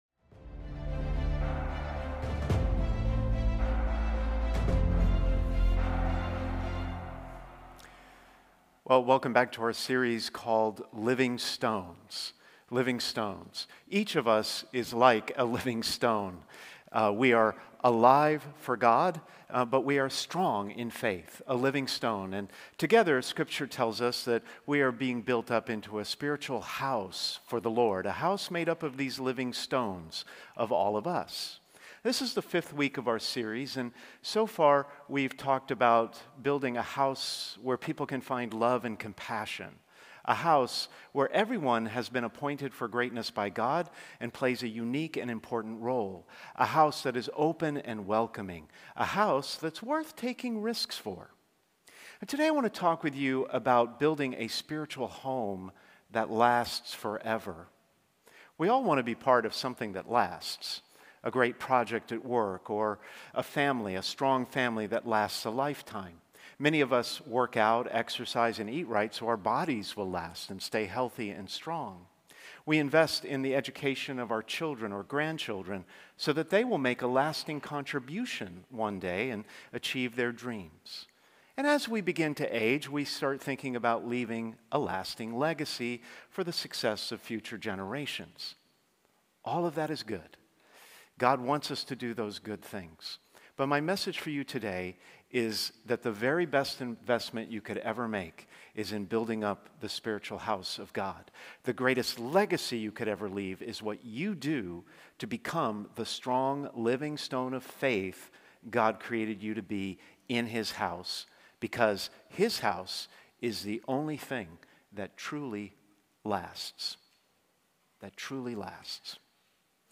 Sermons | St. Hilary Church